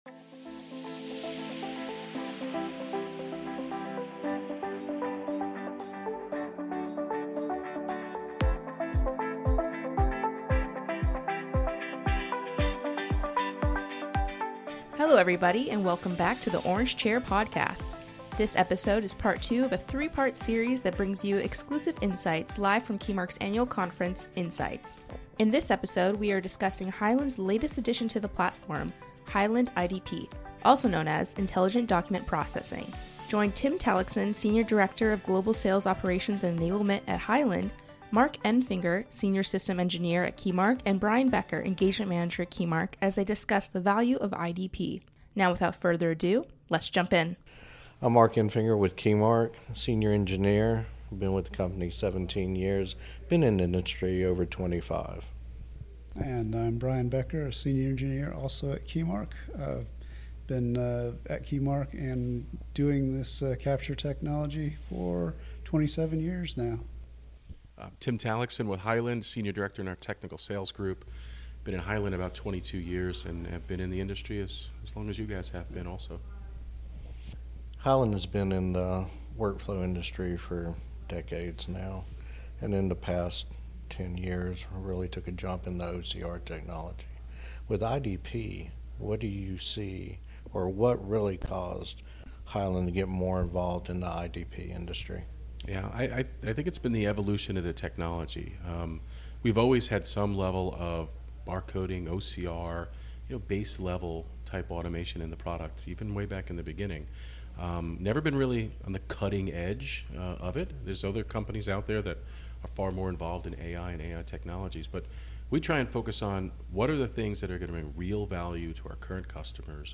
This episode is part two of our three-part series that brings exclusive insights from our premier event that navigates the future of automation for businesses!